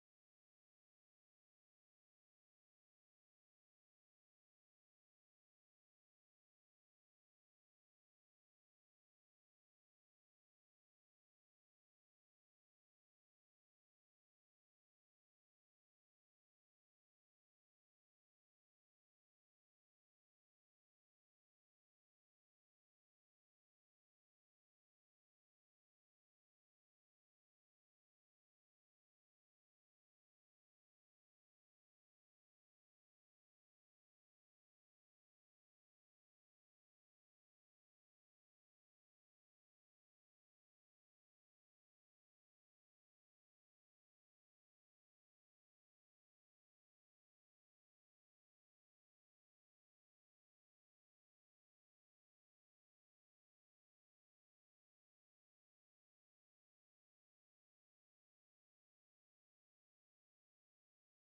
WarZone Destroyed city VFX Green sound effects free download